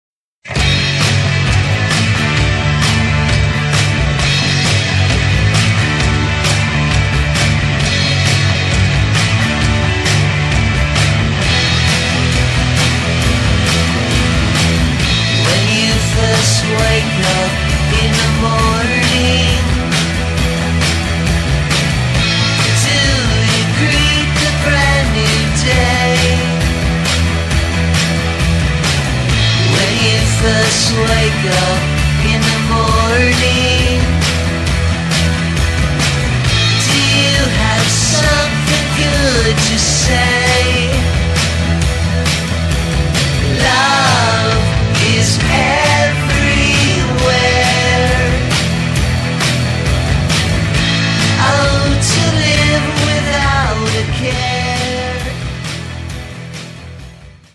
gleaming
Flawless pop rock, indeed.